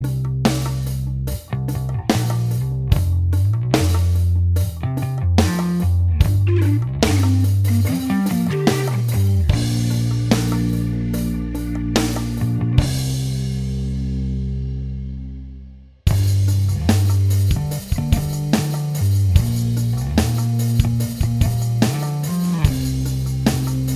Minus Guitars Rock 3:53 Buy £1.50